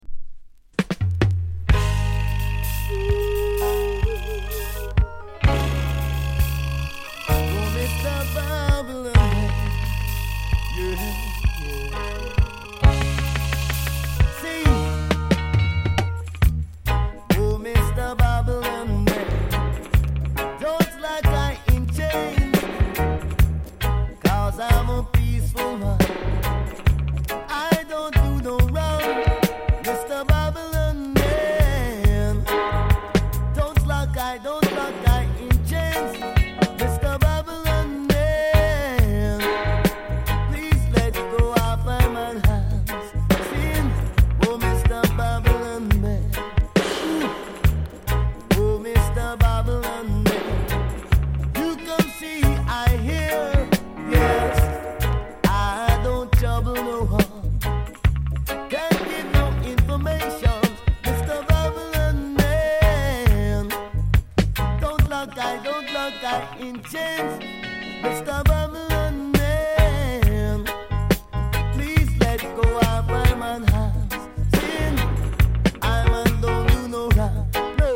高品質 ONE DROP～ROOTS